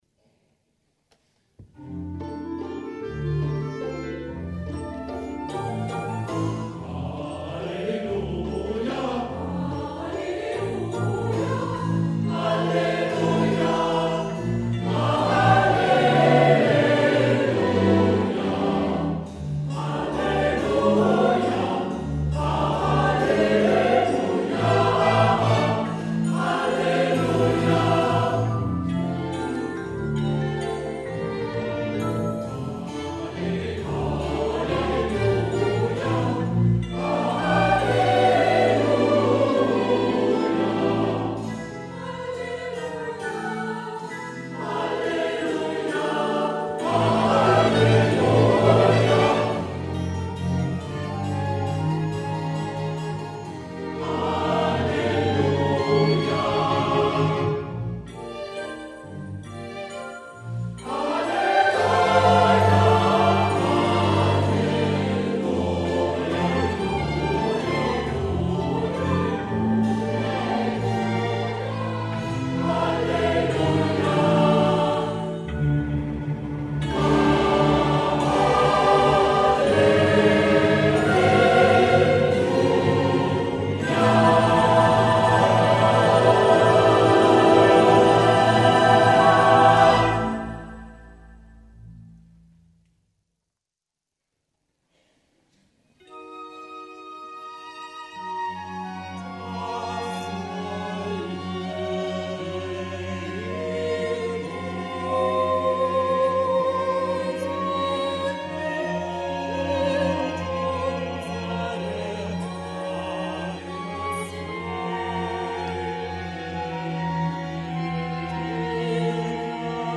Oratorium für Soli, Chor und Orchester
geistl_osteroratorium-finale.mp3